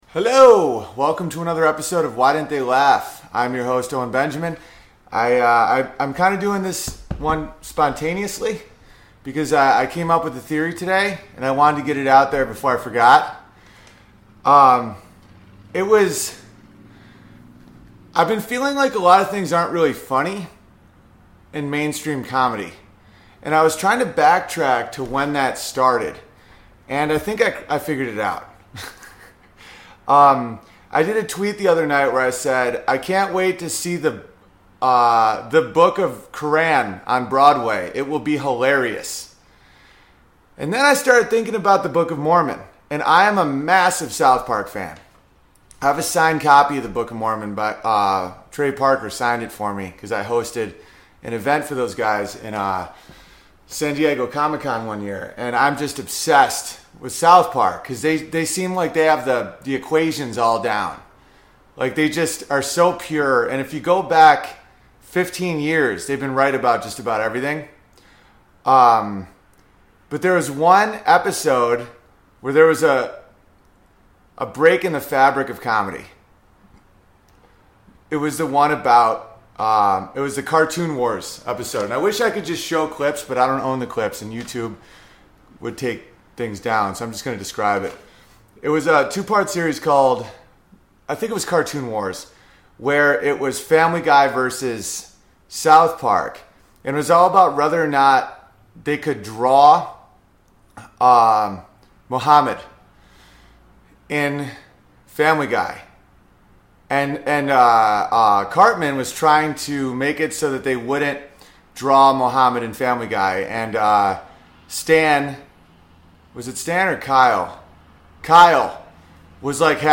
I go off on some fun tangents, some serious ones, play a funny clip where I quickly made friends with some Indians, good times.